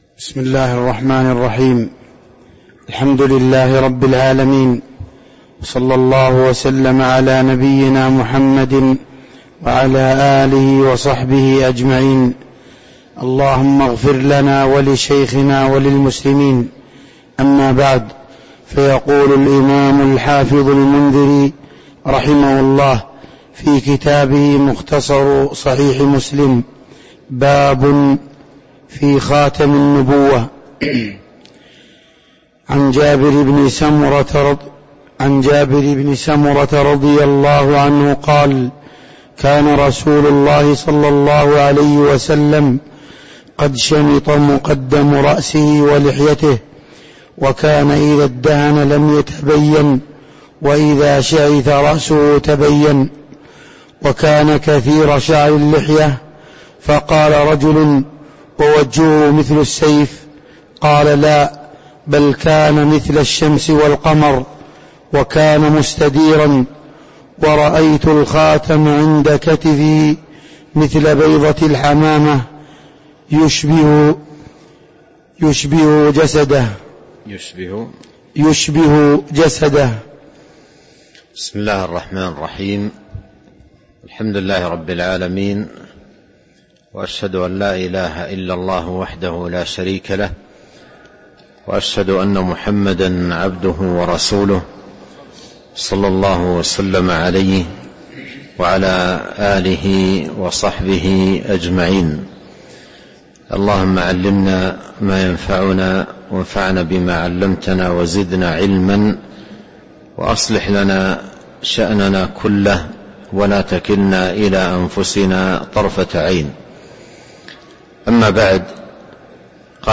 تاريخ النشر ٢٧ شعبان ١٤٤٣ هـ المكان: المسجد النبوي الشيخ